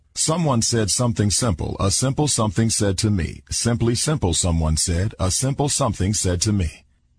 tongue_twister_01_01.mp3